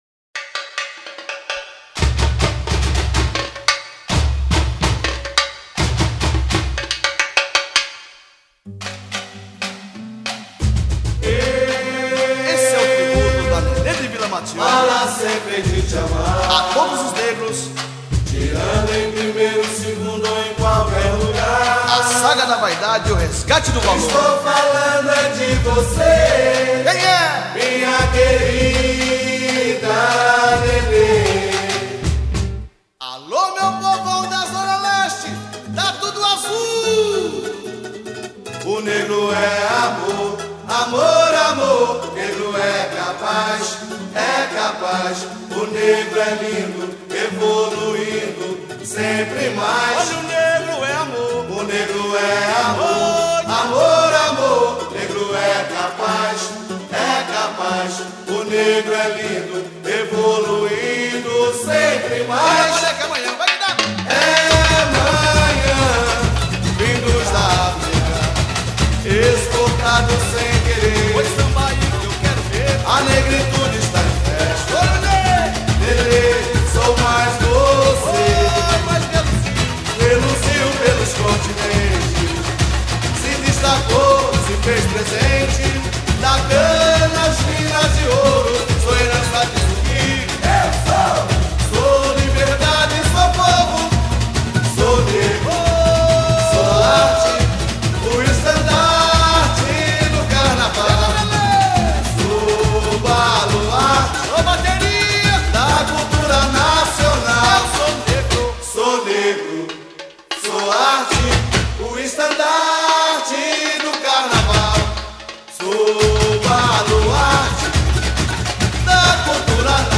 samba-enredo